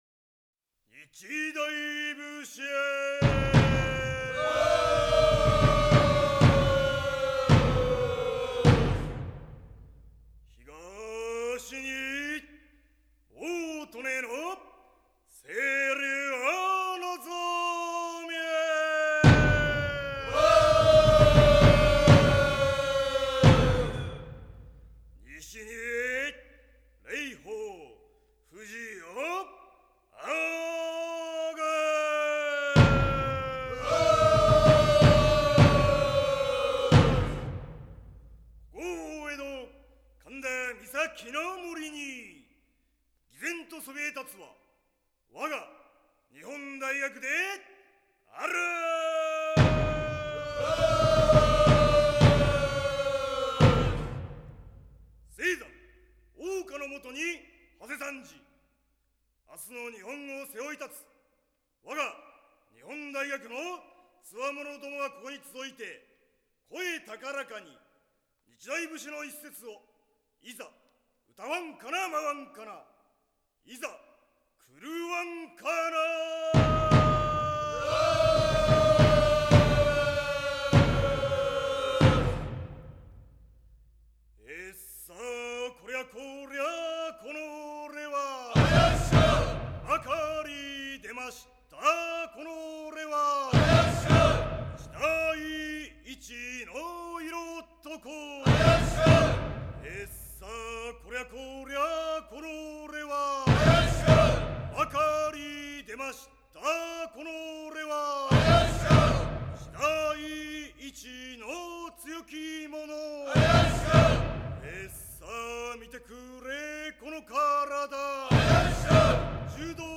※（   ）内 拍手隊